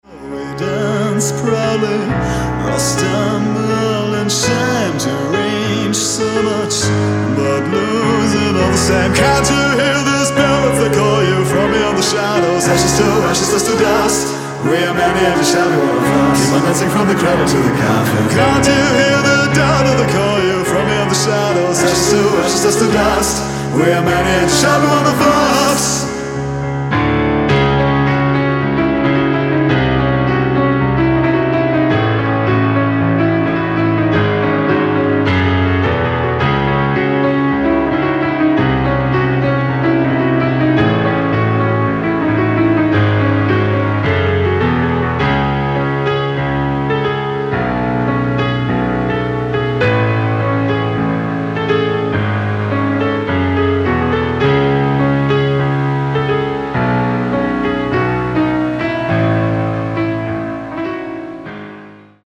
Alternative Rock, Batcave / Deathrock, Gothic-Rock